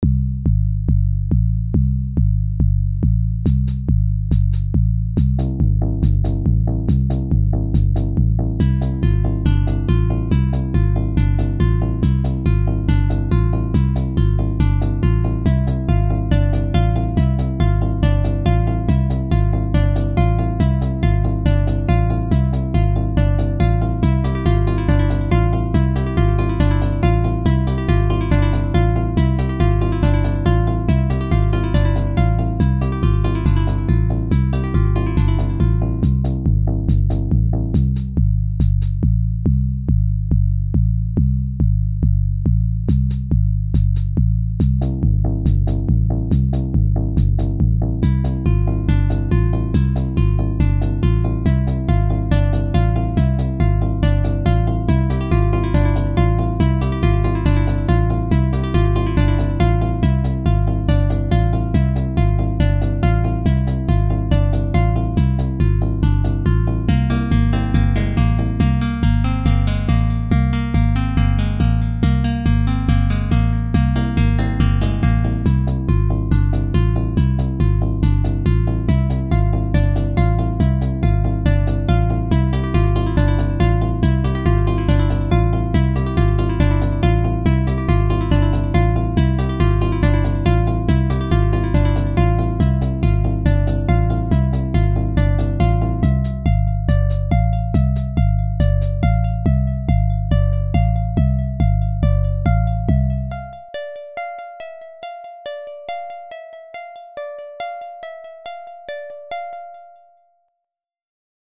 Flicker - a fun little fast tempo song, with a playful main melody.